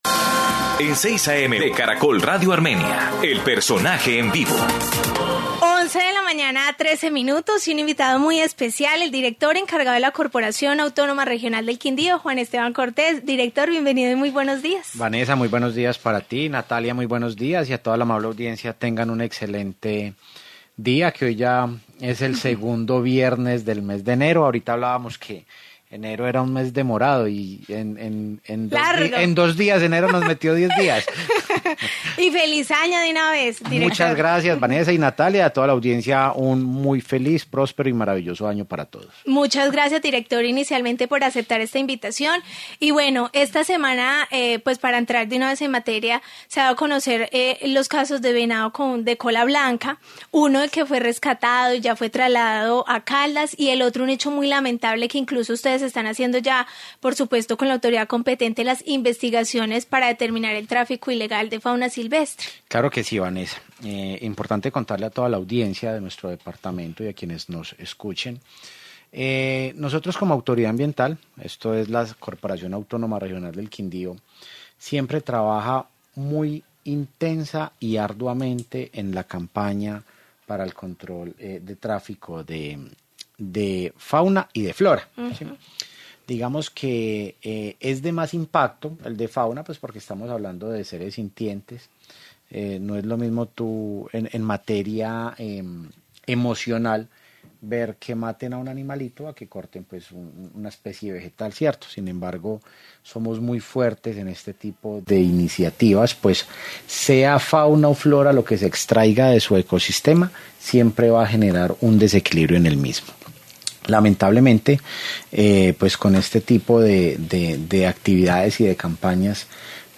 Director encargado de CRQ, Juan Esteban Cortés